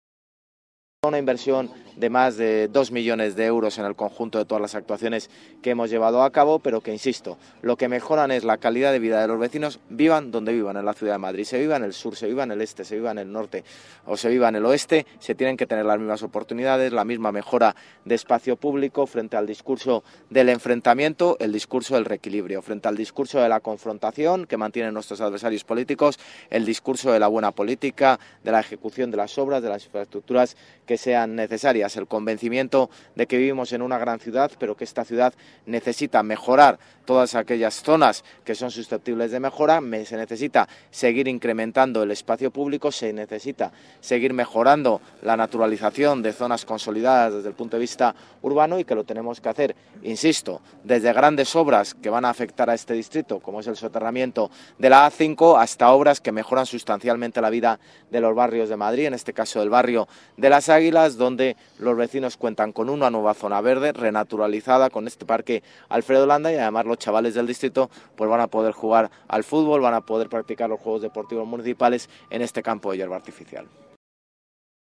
Nueva ventana:Declaraciones del alcalde, José Luis Martínez-Almeida